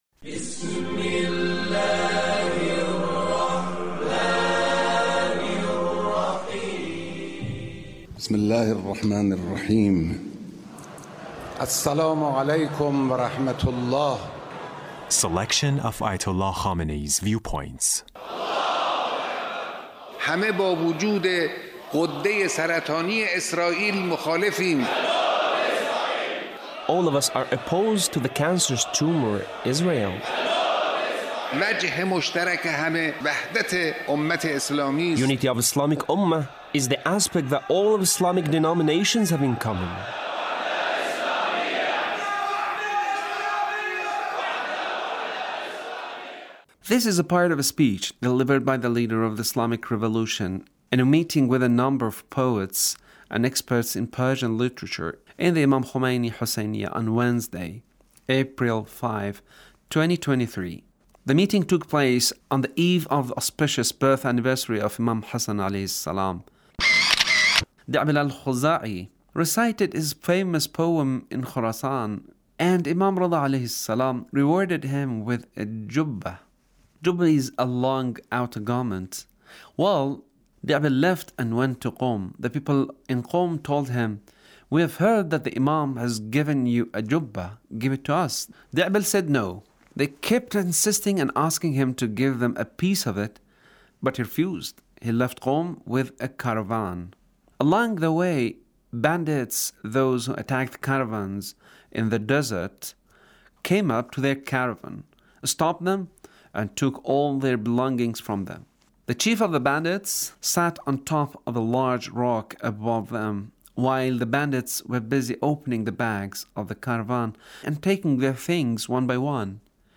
Leader's Speech on Poet's Gathering